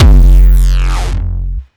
puredata/resources/808_drum_kit/classic 808/Ramen Pedal.wav at cf8e1b7e857aa28113f06ca140d8372ee6167ed9
Ramen Pedal.wav